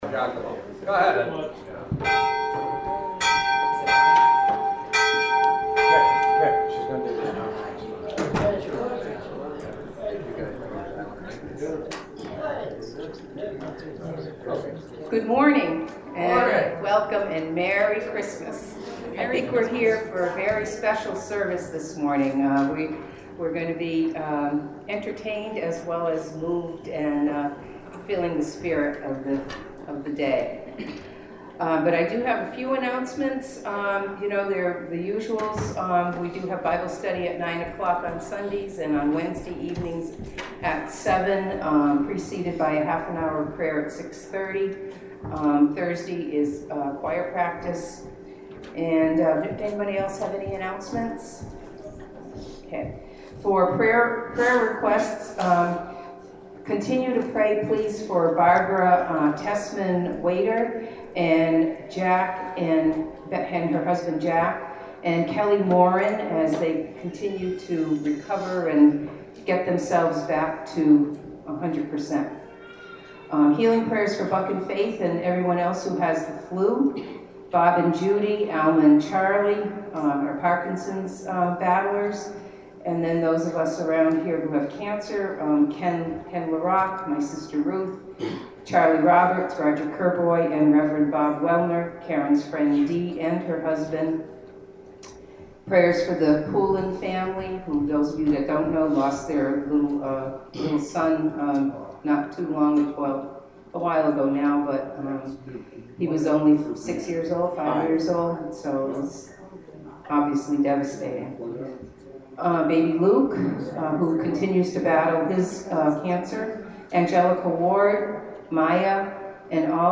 December 25th, 2016 Christmas Day Service Podcast
Welcome to the December 25th, 2016 Christmas Day Service Podcast.